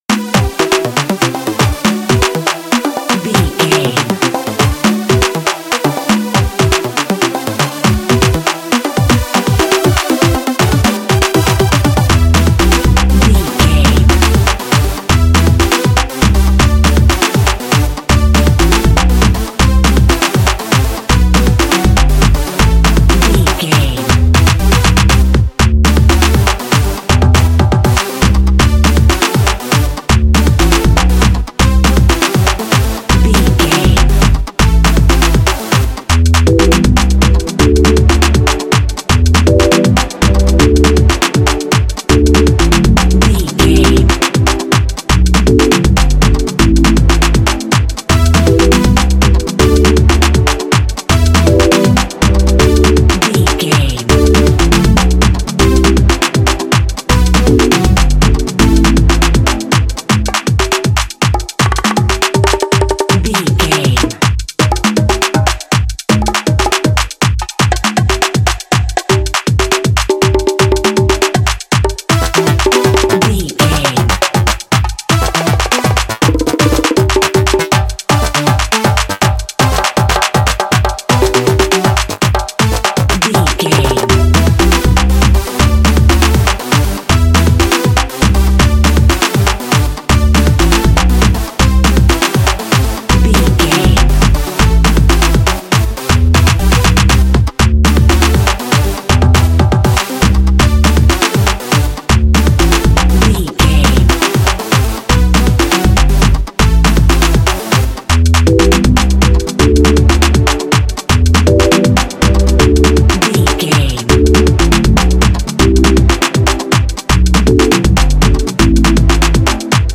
Pulsing with fast-paced Cameroon Mbole rhythms
Its dynamic solos fuel adventure and excitement.
Fast paced
In-crescendo
Uplifting
Ionian/Major
Fast
energetic
festive
intense